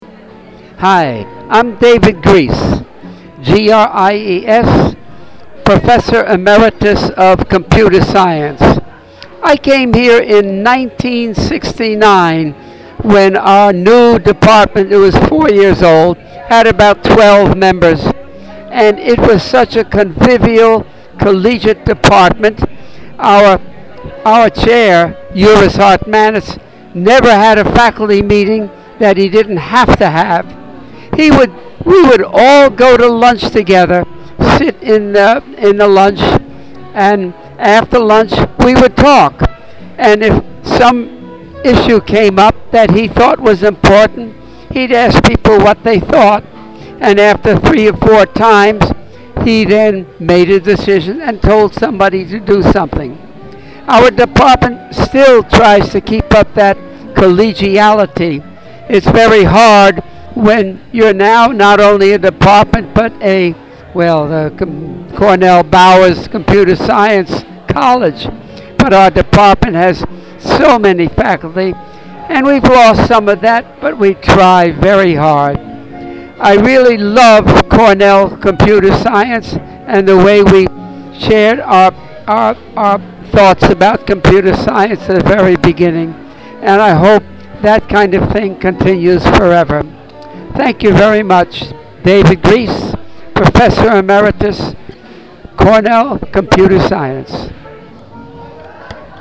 At the annual retirement reception in May, retired faculty have the opportunity to record a memory from their time at Cornell.
Retired faculty member recording a memory from their time at Cornell.